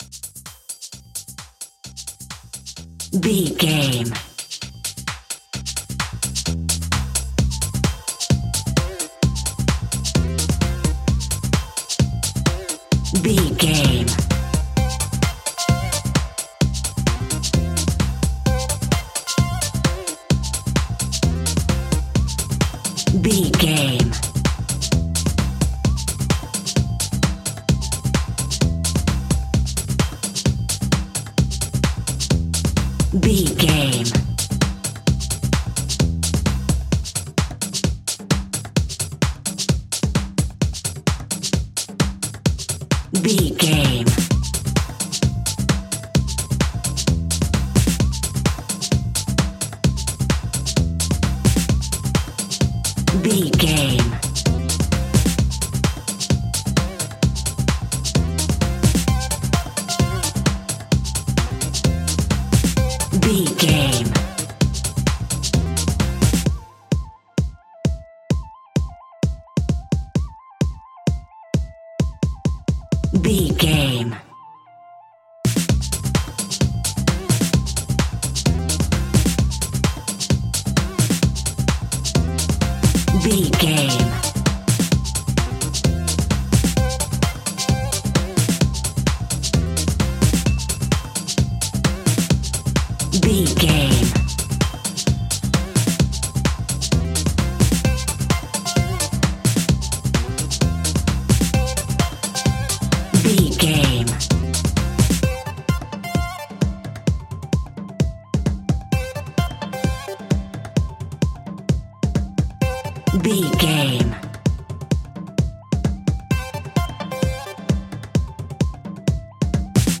Funky House Vintage Music.
Aeolian/Minor
Fast
groovy
uplifting
driving
energetic
bouncy
bass guitar
drums
drum machine
synthesiser
upbeat
funky guitar
clavinet
horns